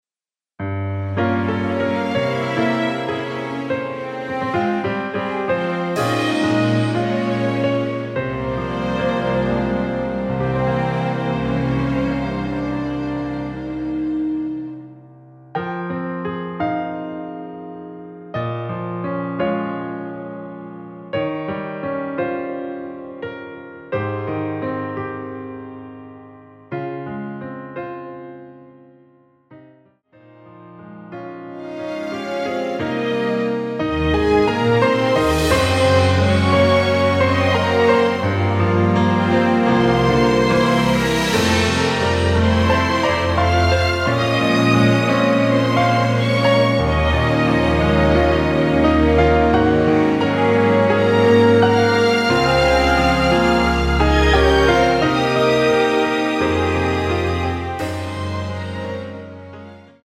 Fm
앞부분30초, 뒷부분30초씩 편집해서 올려 드리고 있습니다.
중간에 음이 끈어지고 다시 나오는 이유는